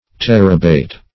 Terebate \Ter"e*bate\, n.